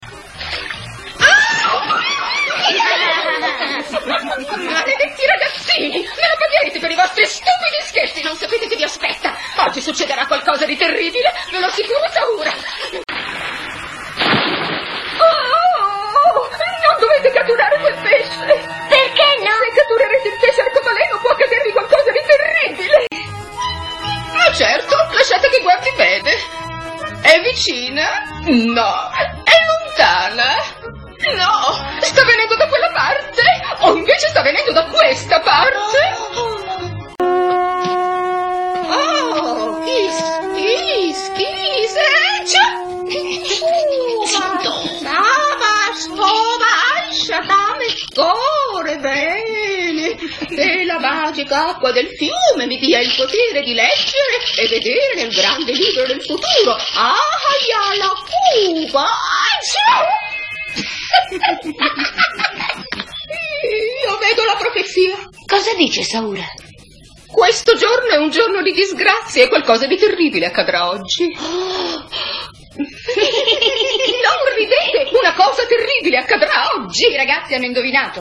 nel cartone animato "Kum Kum il cavernicolo", in cui doppia Shaura.